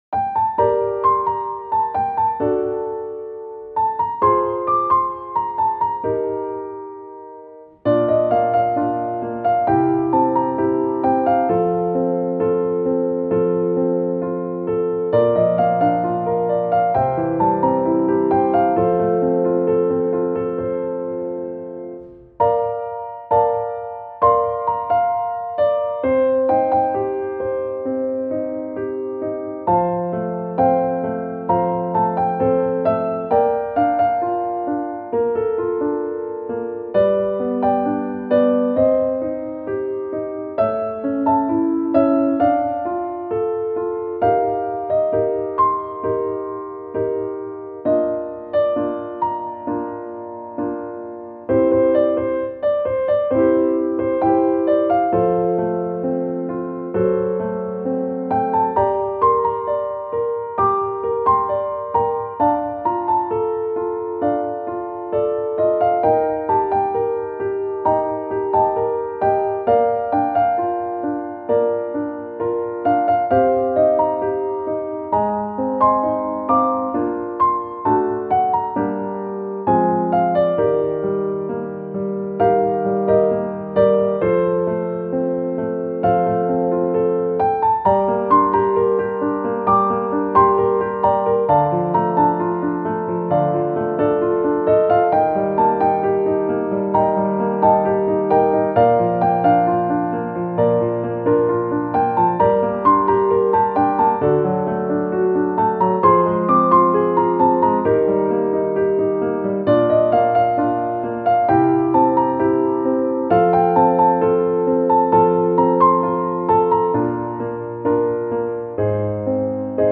イメージ：優しい しっとり   カテゴリ：ピアノ−明るい・軽やか